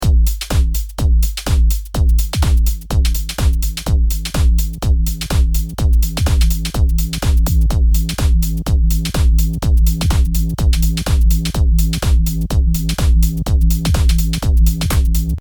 セッティング自体はそん奇抜なことはしていませんが、裏打ちのような感じになっていても波形がリバースなので、ちょっと雰囲気が異なりますね。